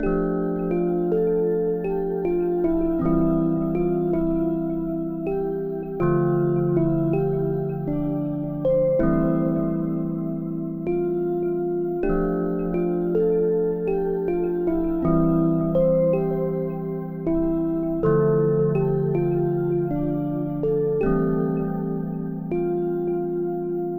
Tag: 140 bpm Hip Hop Loops Pad Loops 2.31 MB wav Key : Unknown